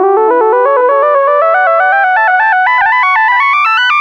Synth 12.wav